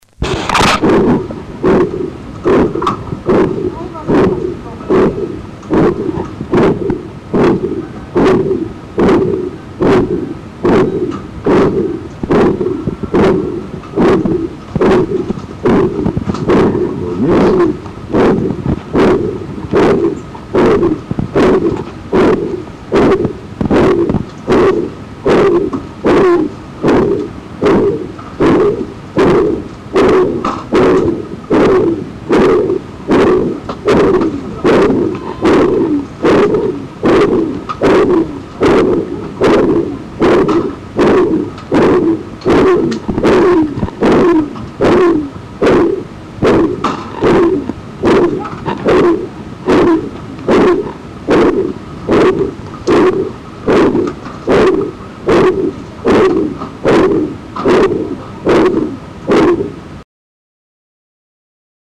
Shuntgeluiden
Kort stotend
Nummer-4-Kort-stotend.mp3